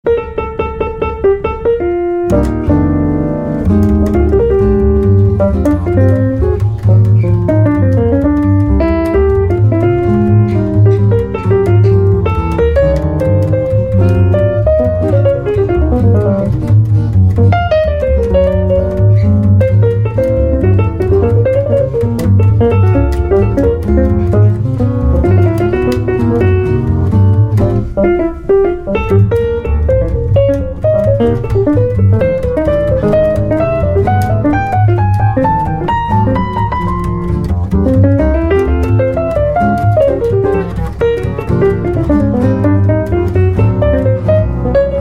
Piano
Guitar